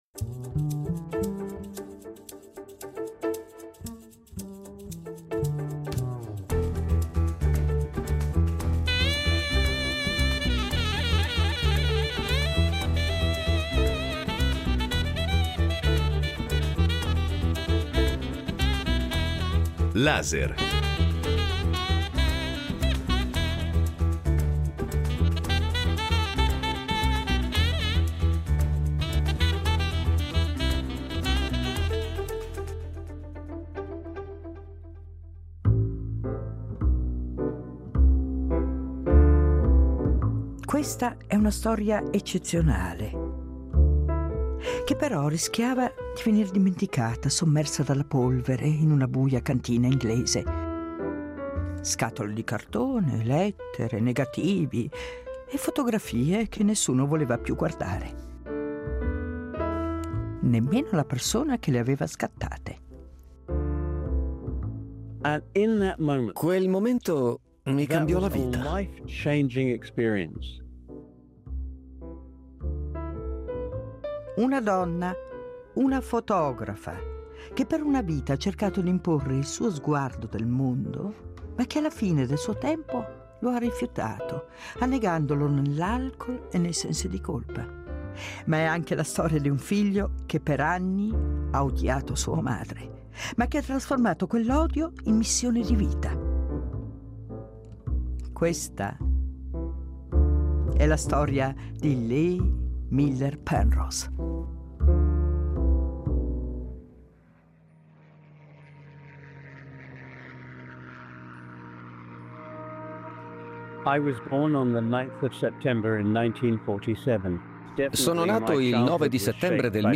Un radiodocumentario che non è solo il ritratto di una grande fotografa, ma il racconto di come il dolore di un figlio, se affrontato, possa trasformarsi in testimonianza e memoria collettiva.